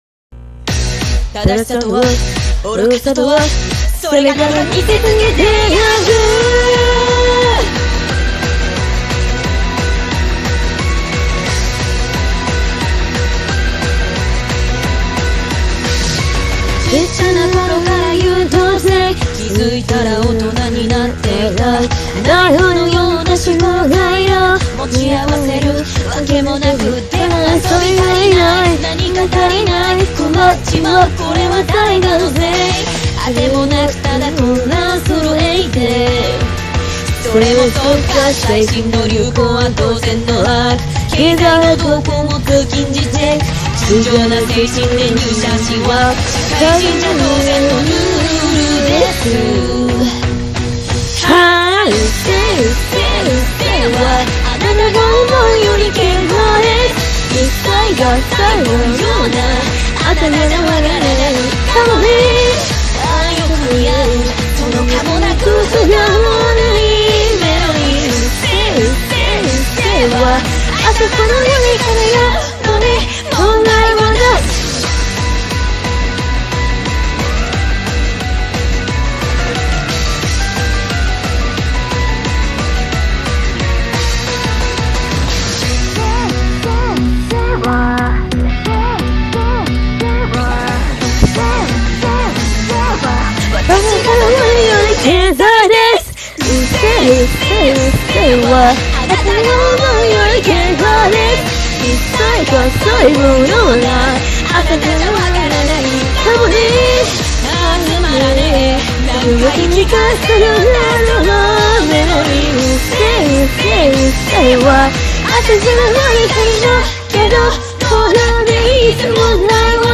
Overlay voice by me.